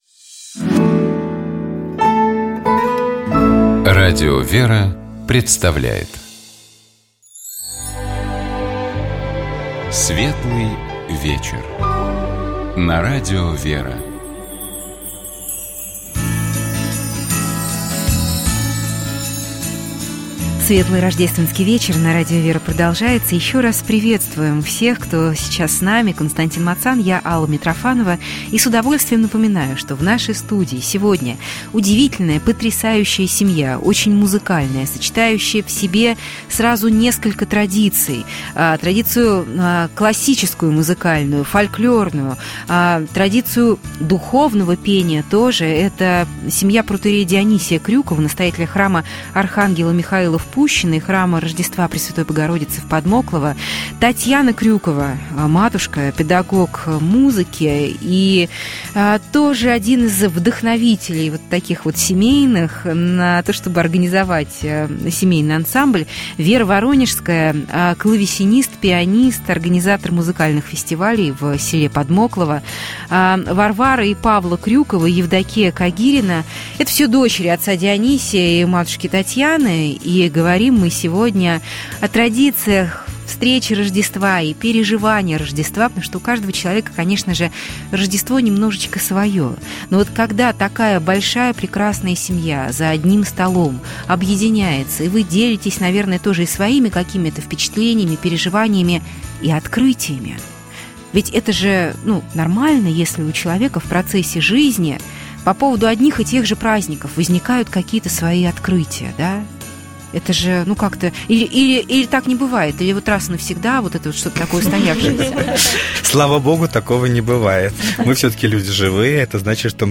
Наши гости рассказали, каким образом в музыке могут раскрываться духовные и богослужебные темы, в том числе тема Рождества Христова. В нашей студии впервые был такой необычный музыкальный инструмент, как клавесин.